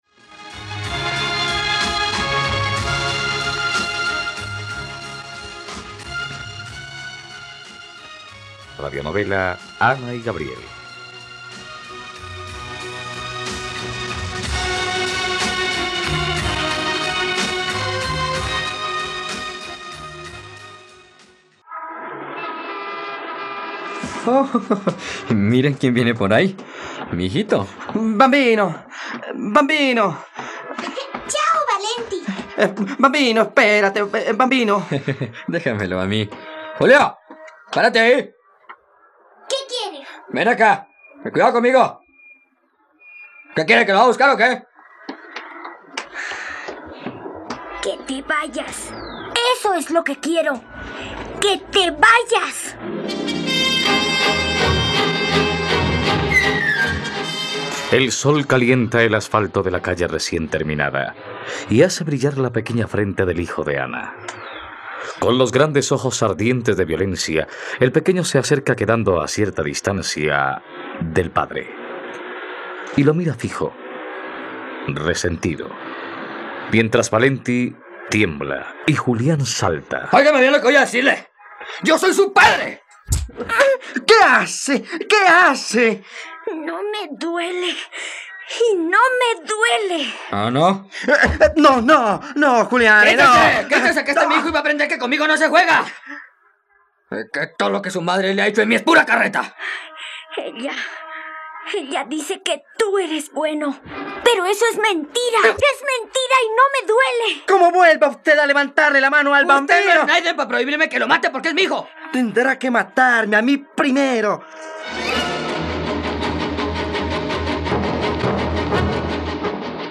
..Radionovela. Escucha ahora el capítulo 74 de la historia de amor de Ana y Gabriel en la plataforma de streaming de los colombianos: RTVCPlay.